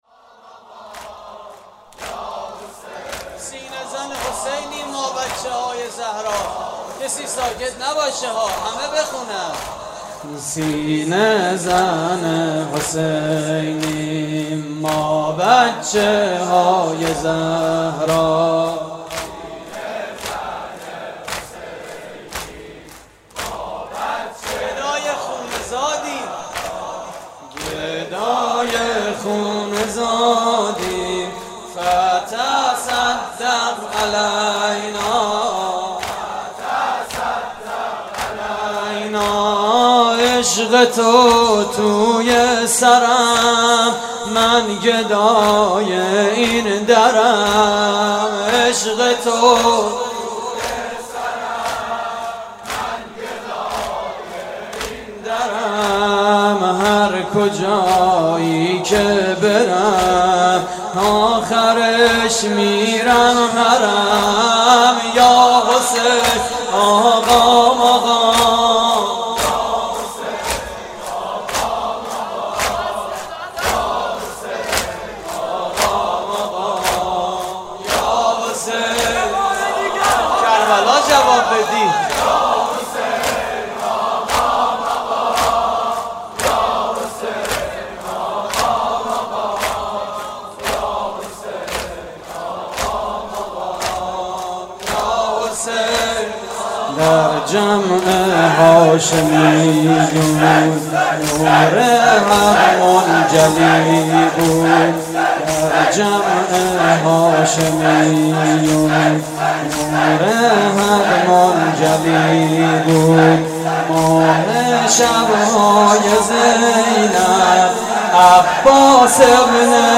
صوت مراسم: